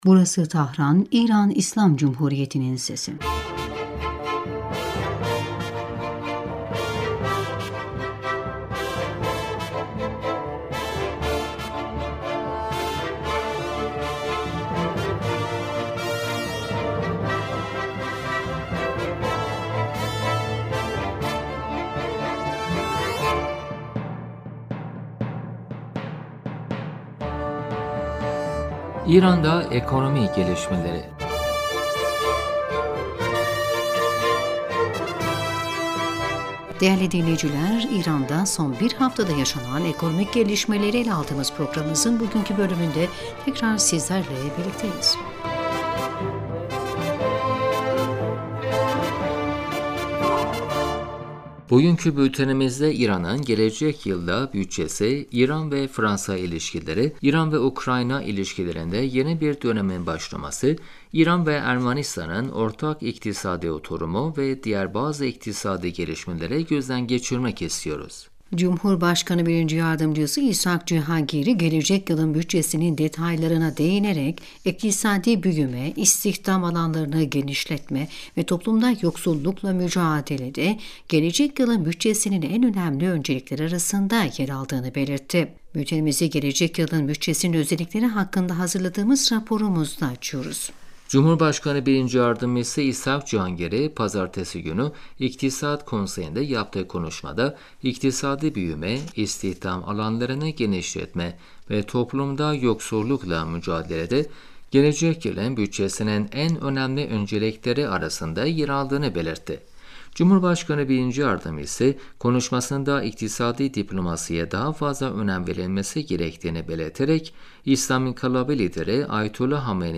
Bugünkü bültenimizde İran’ın gelecek yılda bütçesi, İran ve Fransa ilişkileri, İran ve Ukrayna ilişkilerinde yeni bir dönemin başlaması, İran ve Ermenistan...